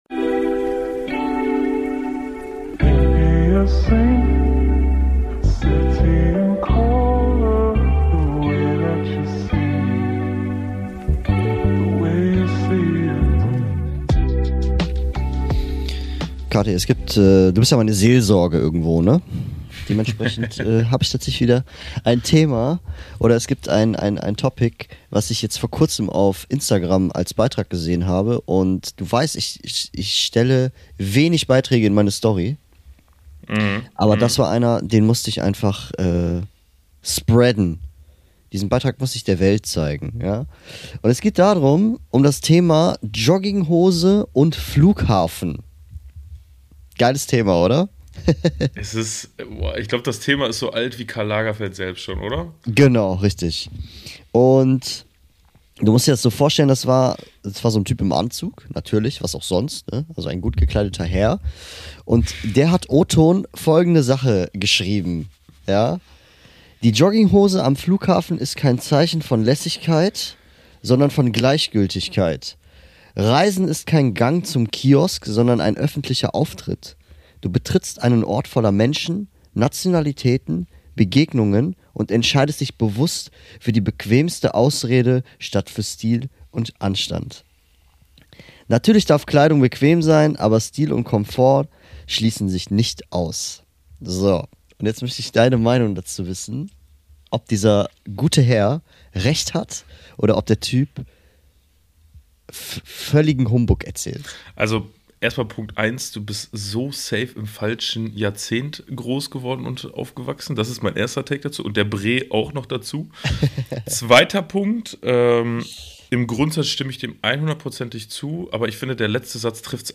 In dieser Folge gibt’s wieder einen entspannten Freetalk aus unserem Wandschrank.